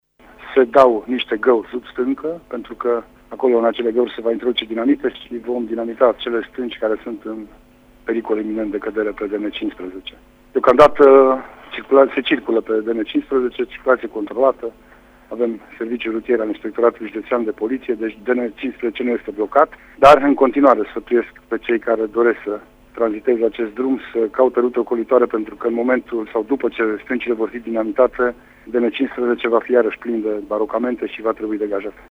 Deasemenea, prefectul a precizat că în zonă circulația se desfășoară controlat: